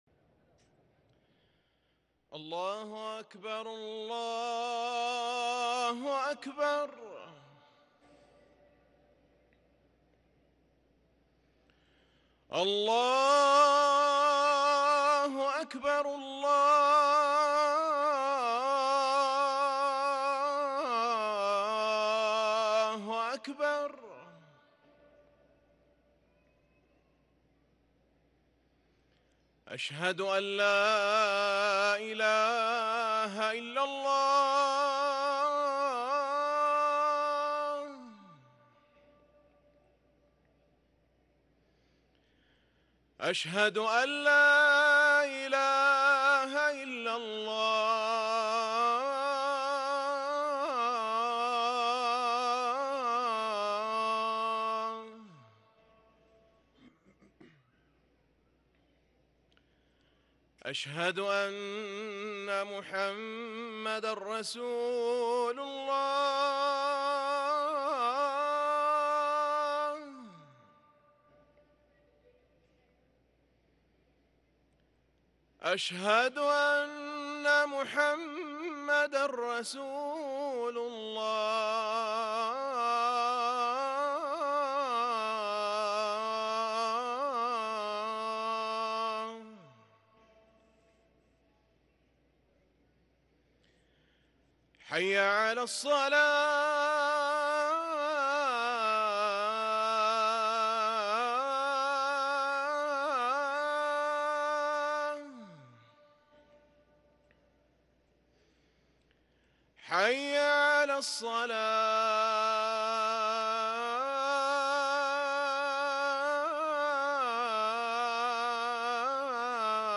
اذان الظهر للمؤذن